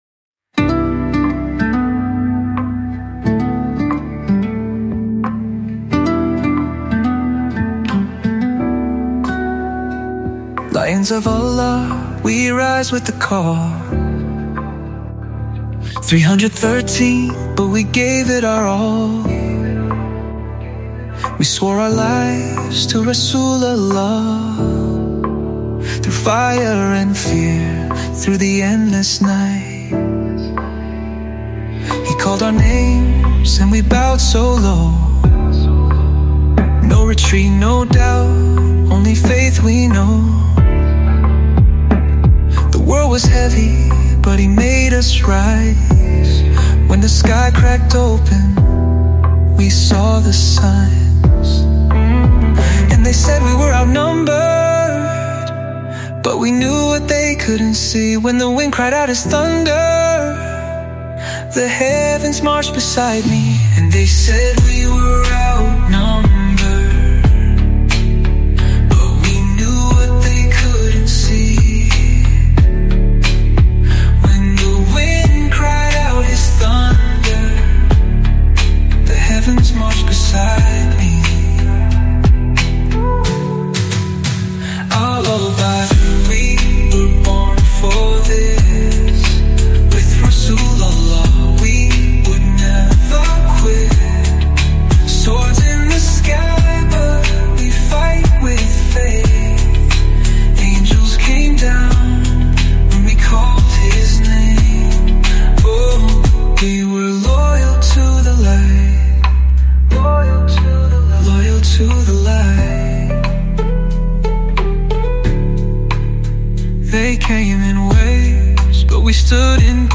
From the LIVE Mawlid